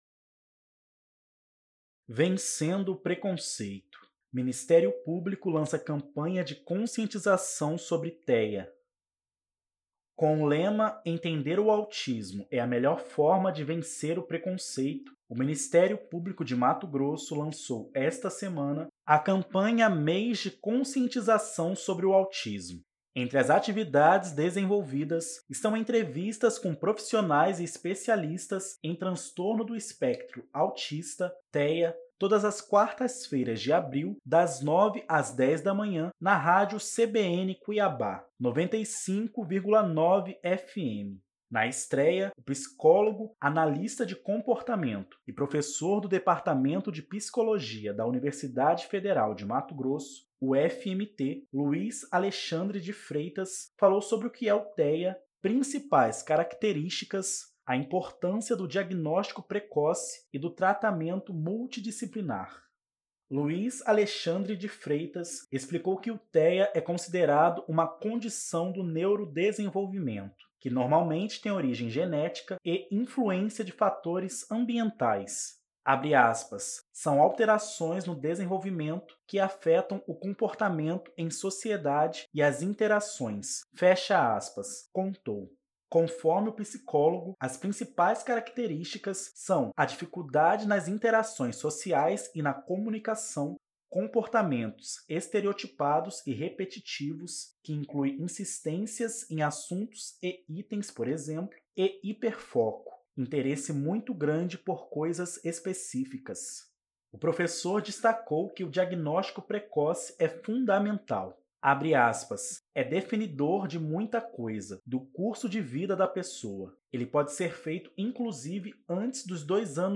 Entre as atividades desenvolvidas, estão entrevistas com profissionais e especialistas em Transtorno do Espectro Autista (TEA), todas as quartas-feiras de abril, das 9h às 10h, na rádio CBN Cuiabá (95,9 FM).